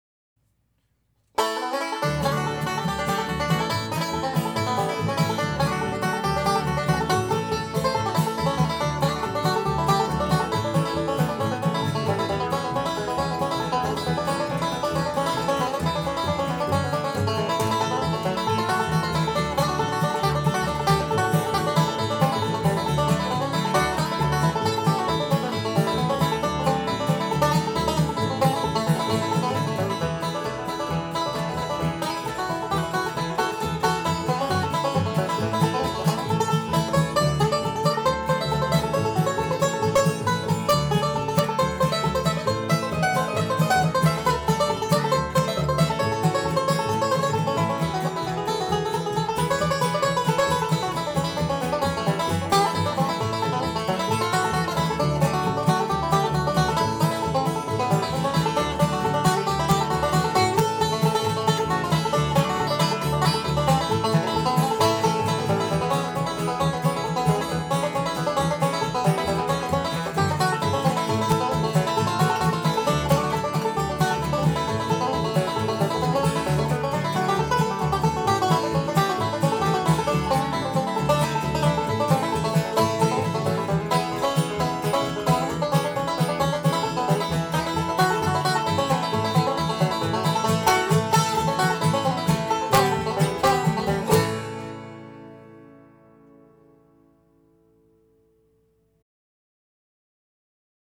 CountryTabs | Brimstone Creek Bluegrass Moss, Tennessee
Guitar/Vocals
Upright Bass/Vocals
Lead Guitar
Banjo